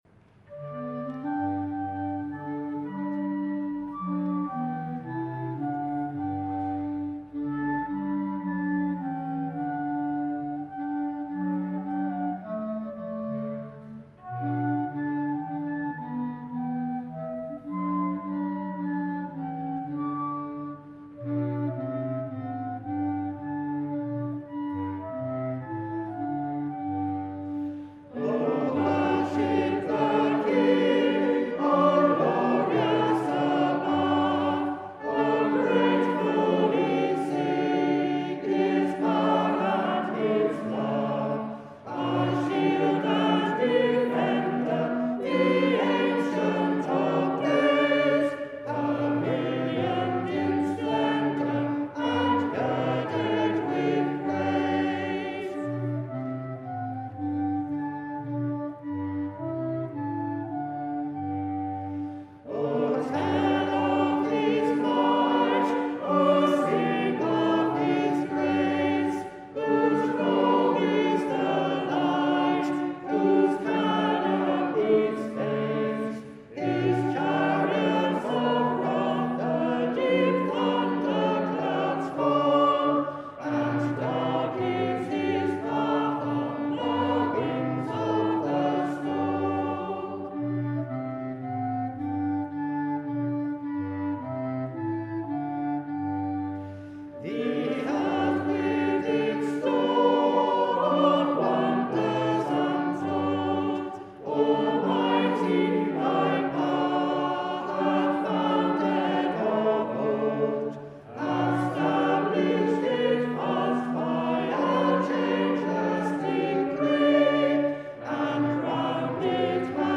O worship the King, all glorious above by London Gallery Quire and the congregation at Evensong at St Andrew's Leytonstone on 23 March 2022
A West Gallery version of this well-known hymn.